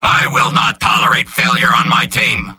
Robot-filtered lines from MvM. This is an audio clip from the game Team Fortress 2 .
{{AudioTF2}} Category:Soldier Robot audio responses You cannot overwrite this file.